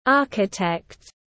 Architect /ˈɑːrkɪtekt/